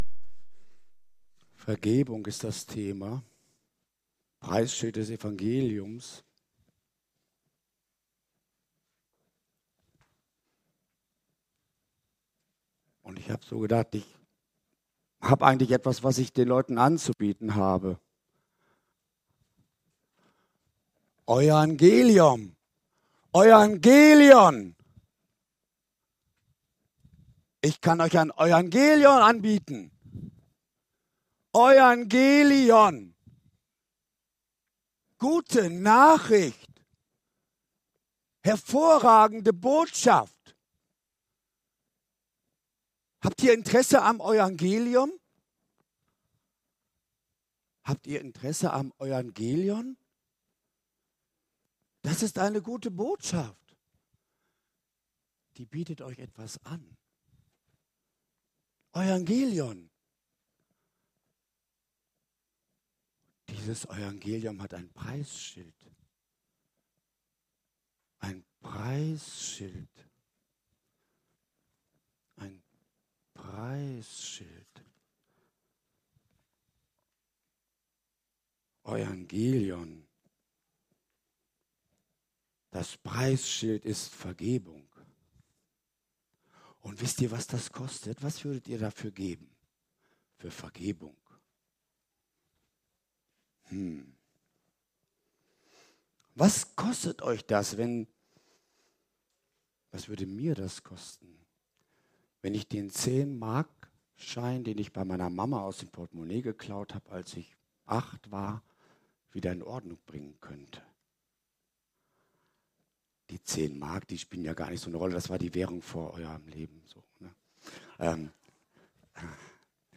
Predigt vom 7.